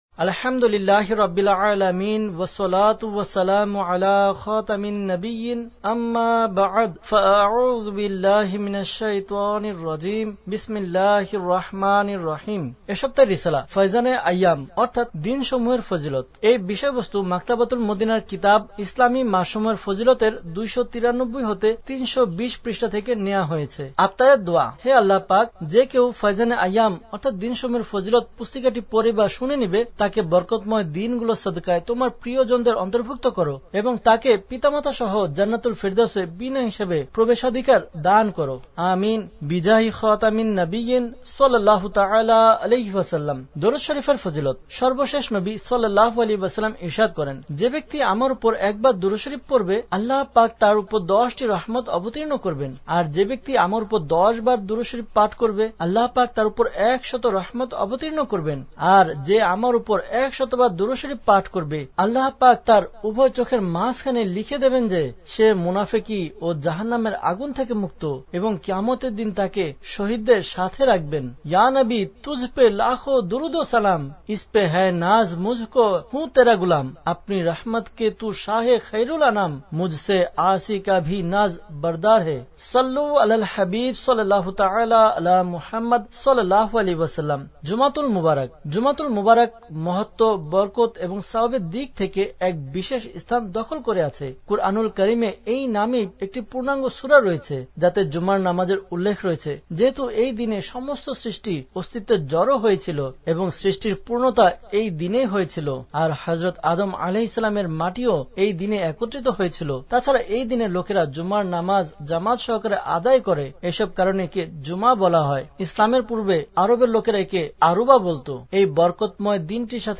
Audiobook – “ফয়যানে আইয়্যাম” (দিনসমূহের ফযিলত) (Bangla)